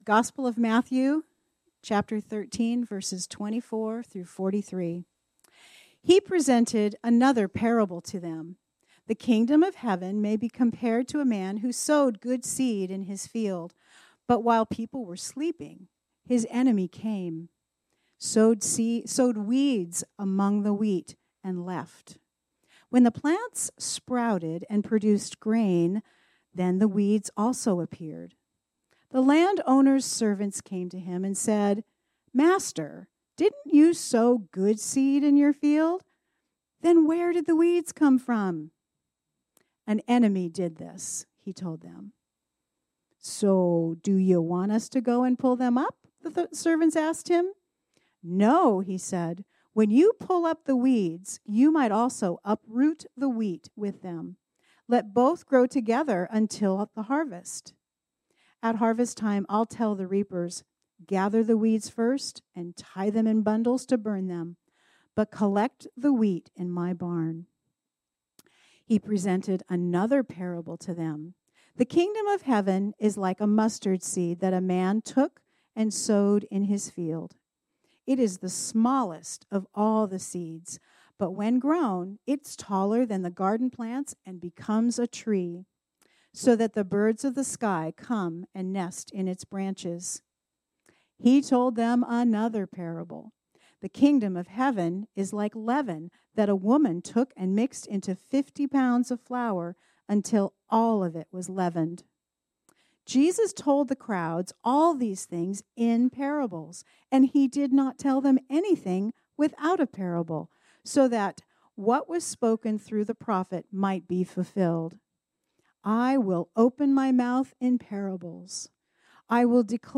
This sermon was originally preached on Sunday, June 16, 2024.